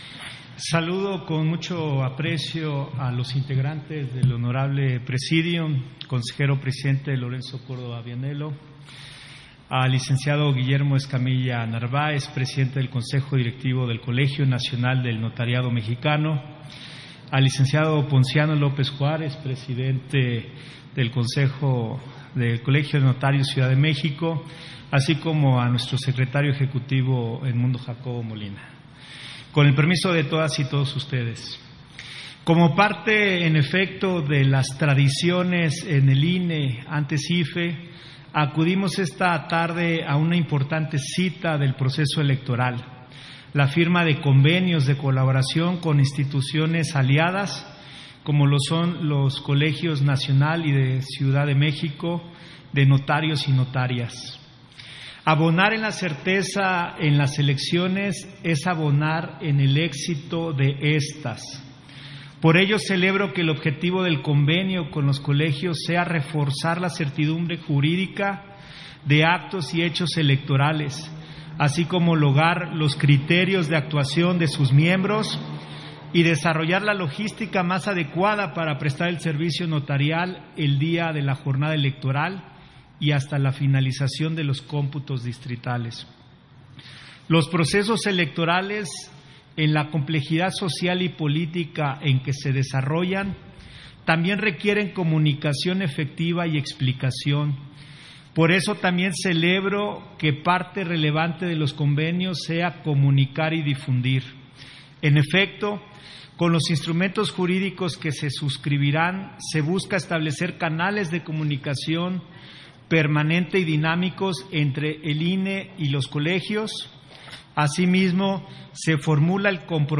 Intervención de José Roberto Ruiz, durante la firma de convenio de colaboración INE – Colegio Nacional del Notariado – Colegio de Notarios de la Ciudad de México